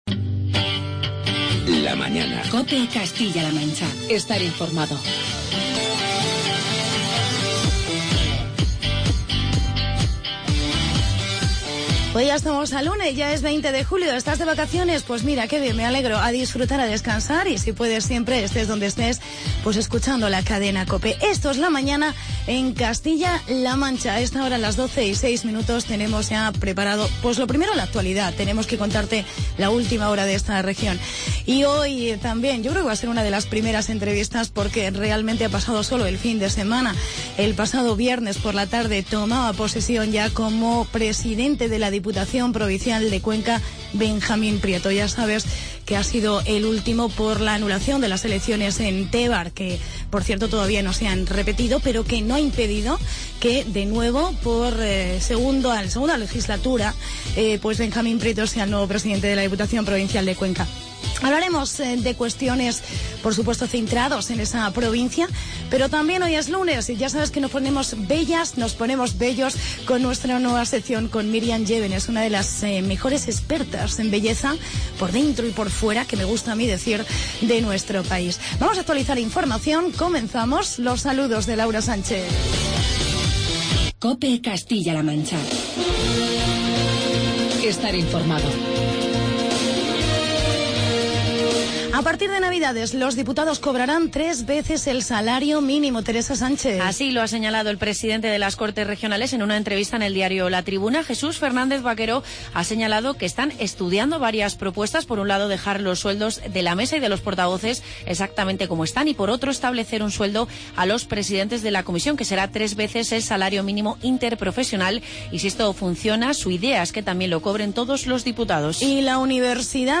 Entrevista a Benjamín Prieto, Presidente de la Diputación de Cuenca